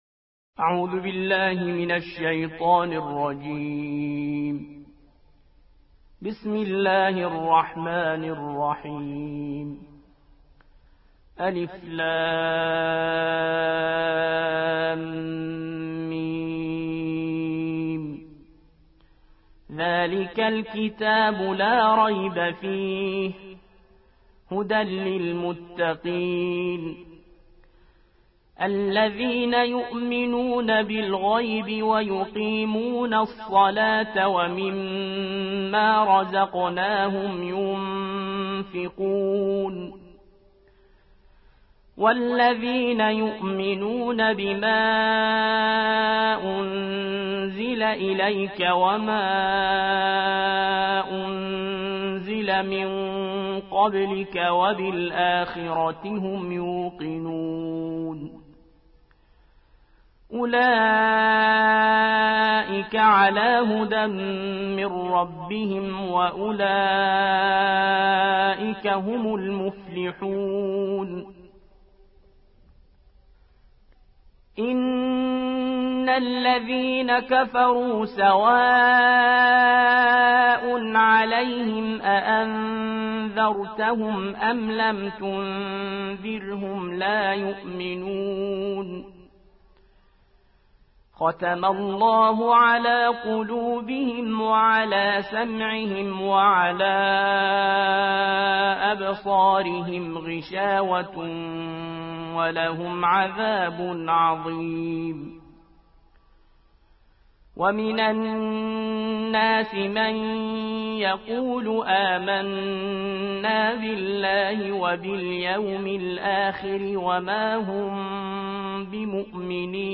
ترتیل سوره بقره